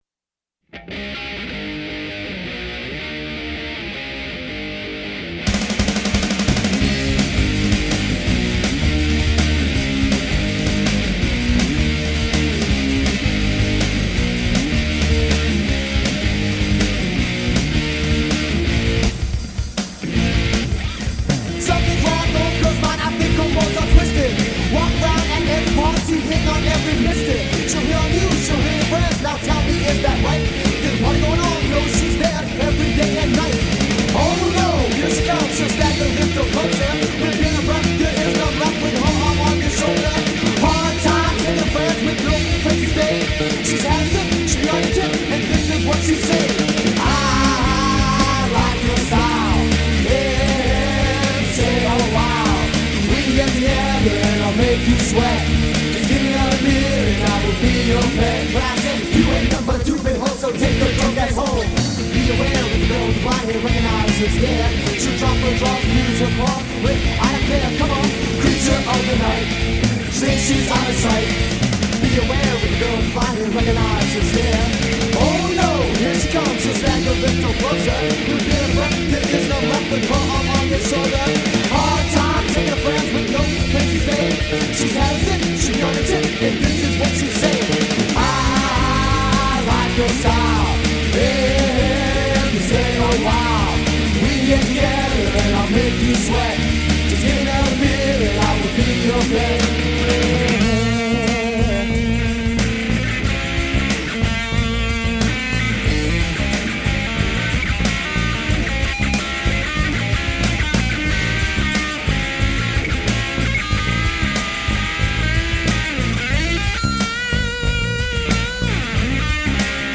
Percussion
Bass
Vocals
Guitar
Indie , Punk , Rock